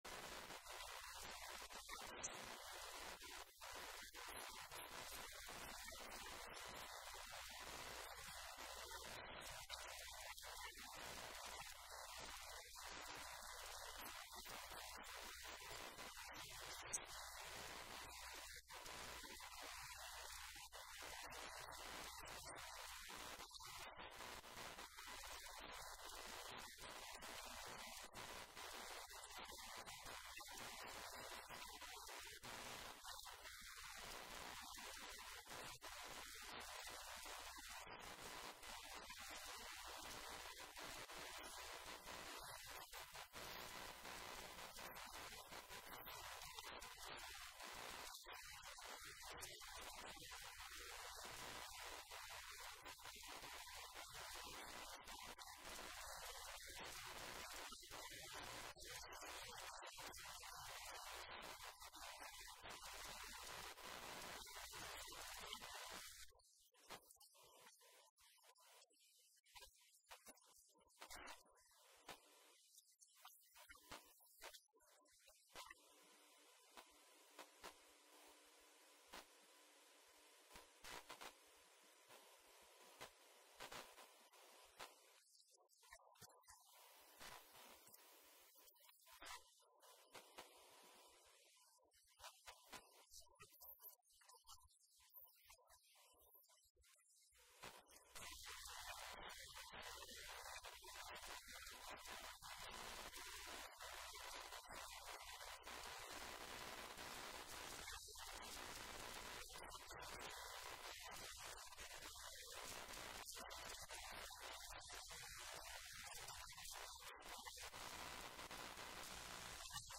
How can you—a disciple of Jesus Christ—produce and facilitate peace in your life despite the presence of difficult people and circumstances? Listen to this sermon to learn how to cultivate an atmosphere of PEACE!